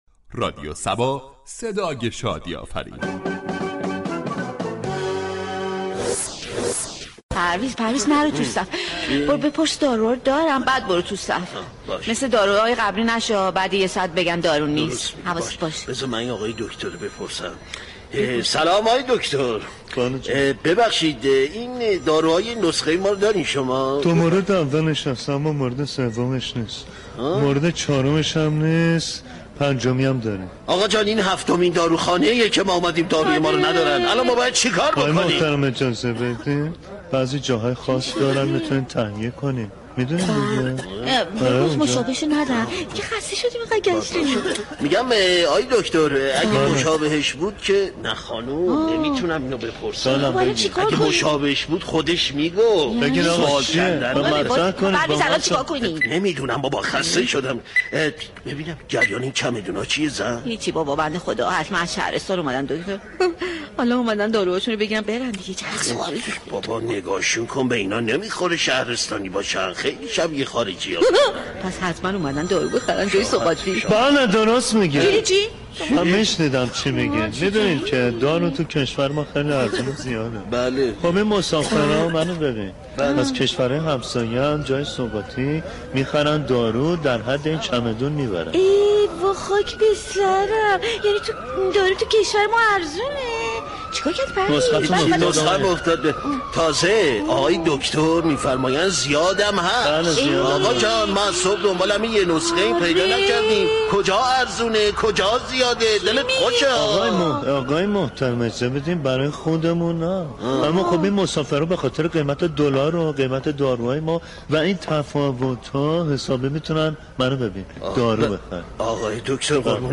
شهر فرنگ در بخش نمایشی با بیان طنز به موضوع " كمبود دارو در داروخانه ها "پرداخته است ،در ادامه شنونده این بخش باشید.